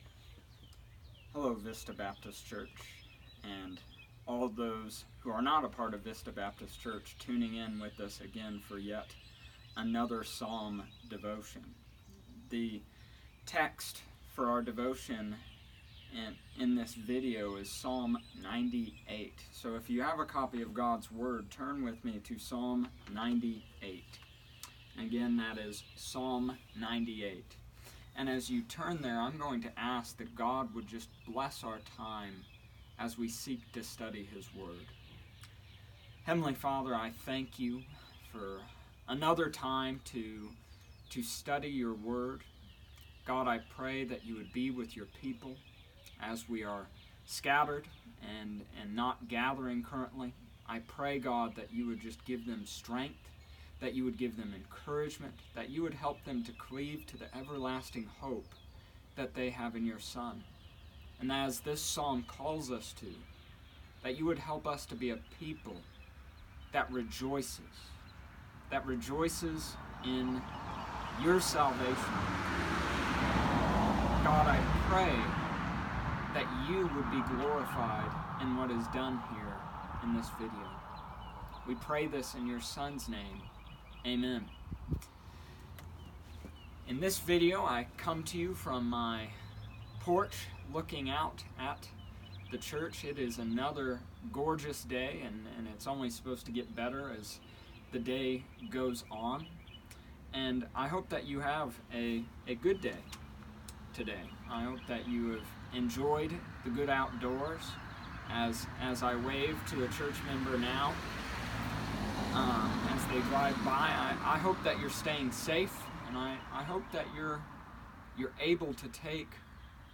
Here is another devotion from the Psalms. May God use it to help you rejoice in Him.